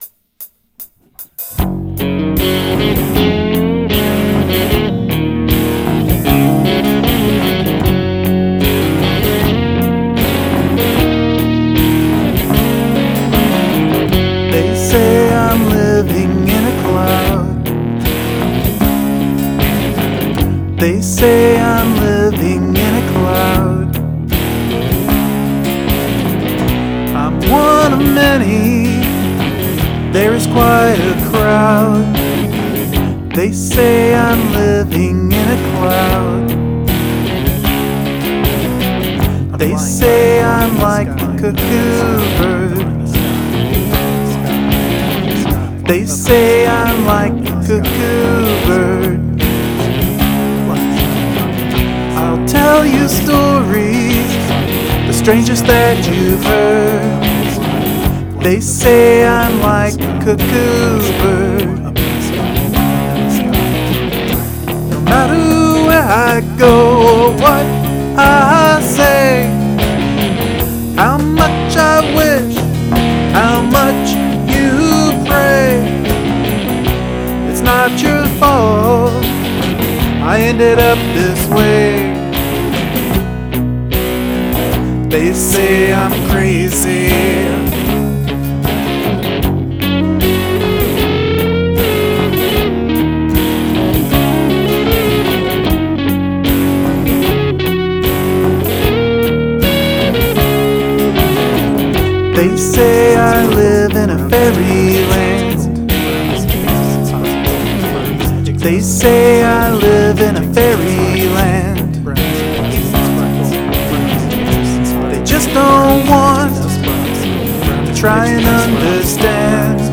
A nice melody, I like the muddy sound.
Love the lead on this, the guitars have a nice sound to them.
Cool song; beautiful and melodic! I loved the guitar sound, too.
Sweet solo! And I like the background talking too.
I love that dirty guitar sound.
I do think the "Flying High up in the sky" background vox distract too much from your main vocals.